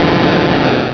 sovereignx/sound/direct_sound_samples/cries/gyarados.aif at 2f4dc1996ca5afdc9a8581b47a81b8aed510c3a8
gyarados.aif